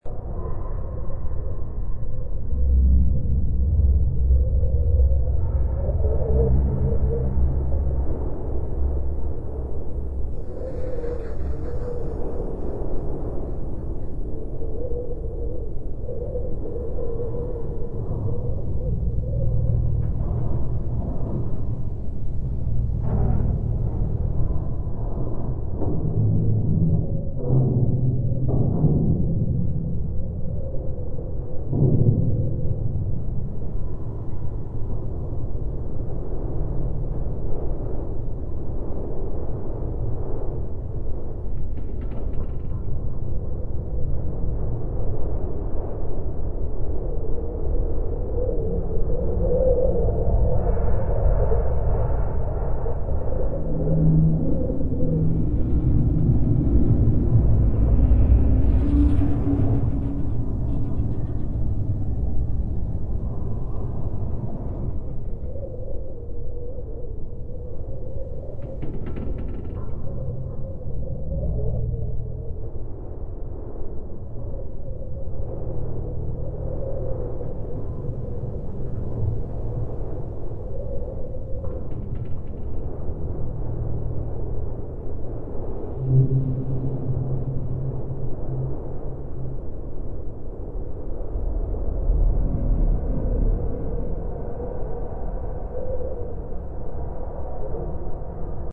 zone_field_debris.wav